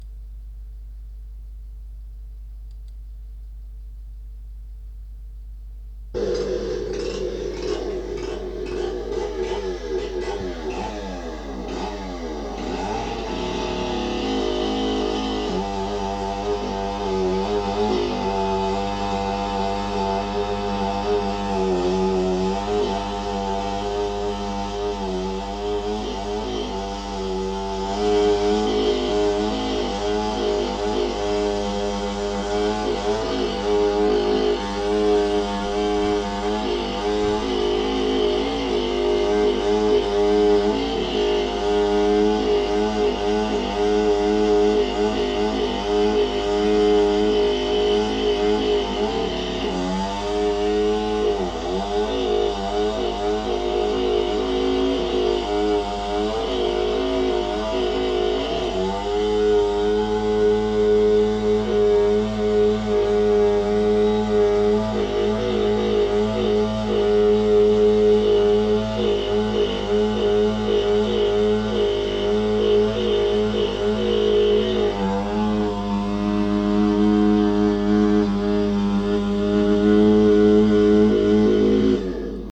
Sonidos de una motosierra - Sonidos de una motosierra (Sonidos) )) 39802
• Categoría: Motosierra
• Calidad: Alta